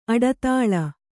♪ aḍatāḷa